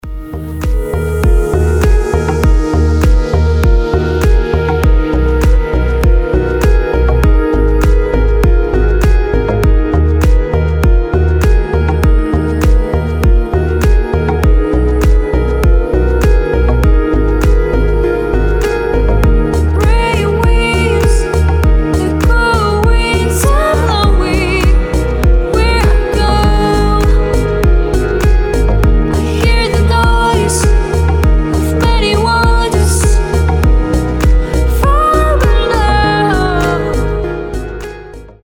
• Качество: 320, Stereo
deep house
грустные
Electronic
красивый женский голос